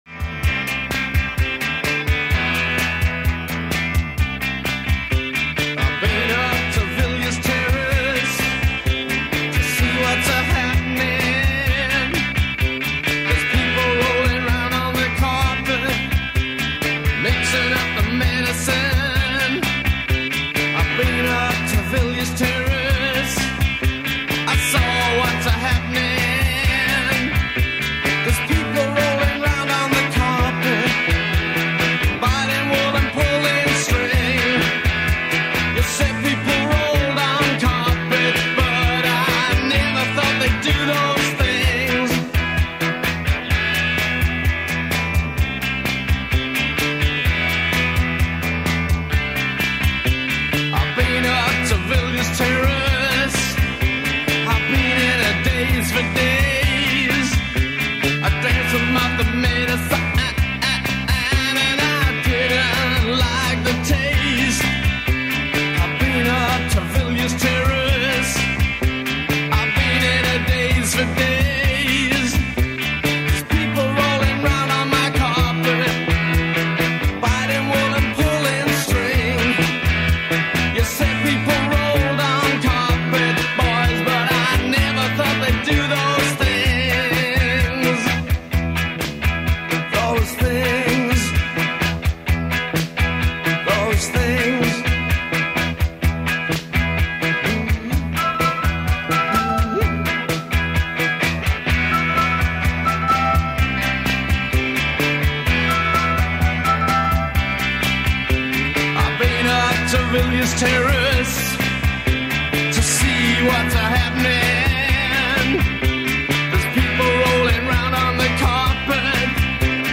in session for John Peel